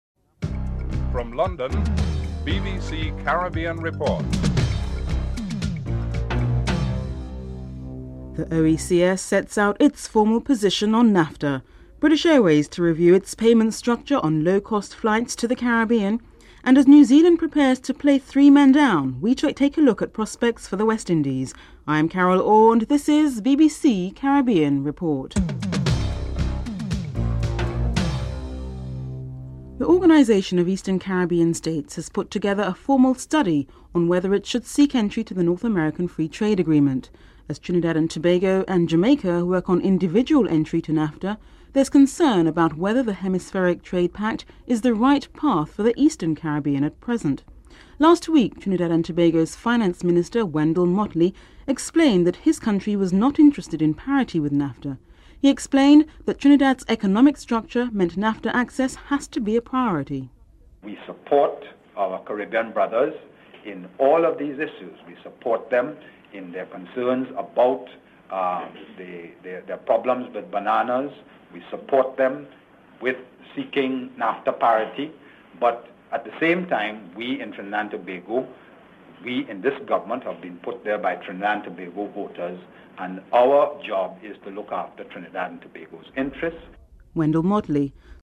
The British Broadcasting Corporation
2. Report on the OECS formal study on whether it should seek entry into NAFTA (00:29-00:59)
4. Interview with Nicholas Brathwaite on his concerns of CARICOM states seeking separate NAFTA entry (01:28-02:22)